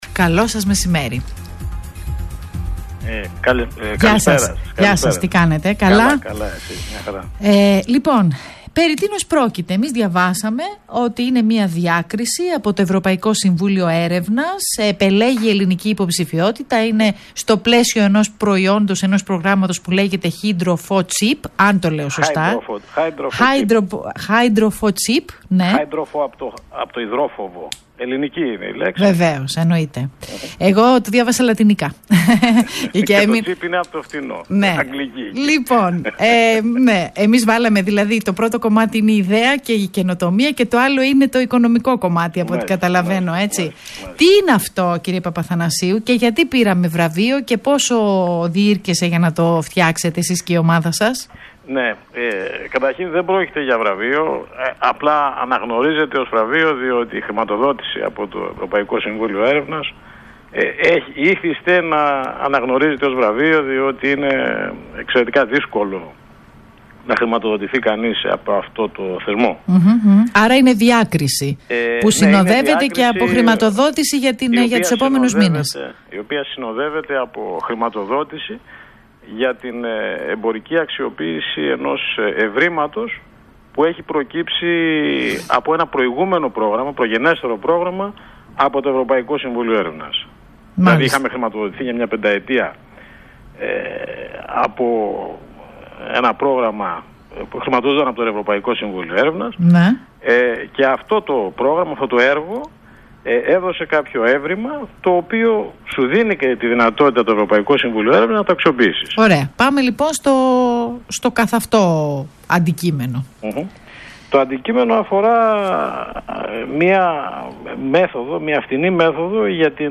ert_interview.mp3